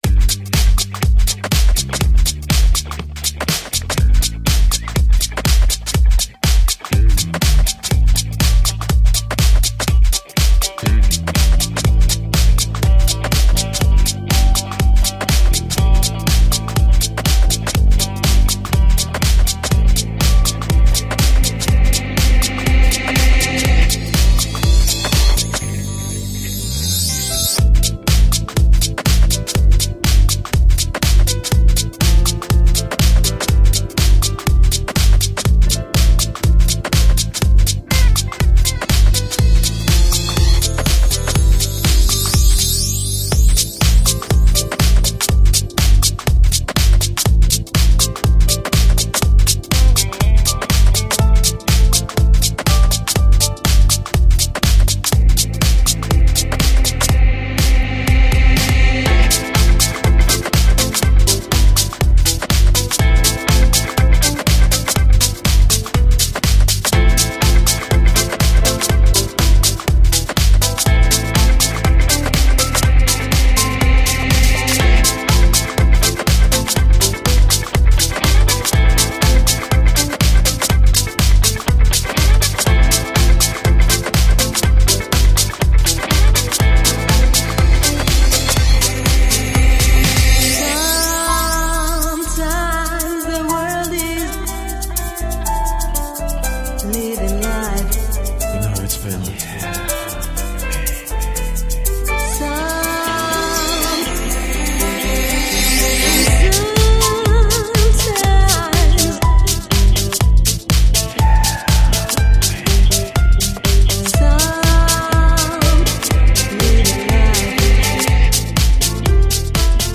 Melancholy, loneliness, desire.
with the sound of the sad piano beautifully played
gracefully captured by the velvety vocal
Deep House